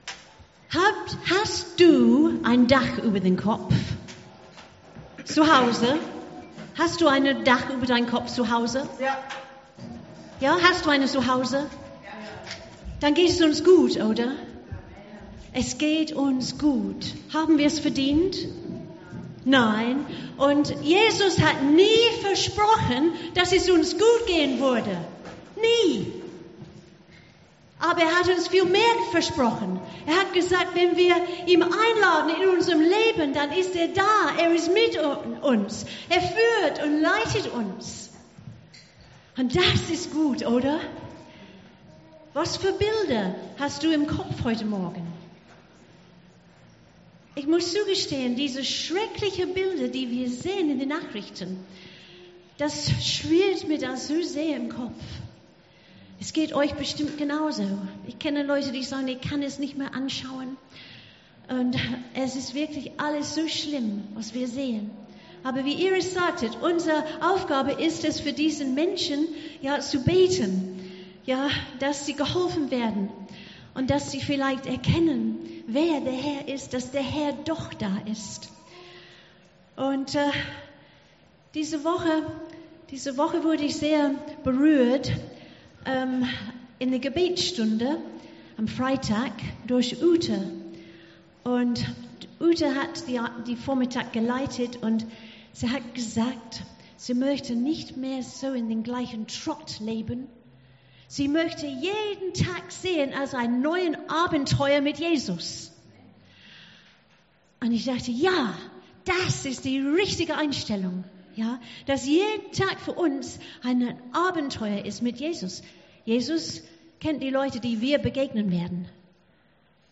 Predigt 18.07.2021